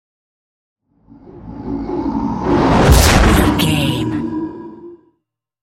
Creature whoosh to hit large
Sound Effects
Atonal
ominous
eerie
woosh to hit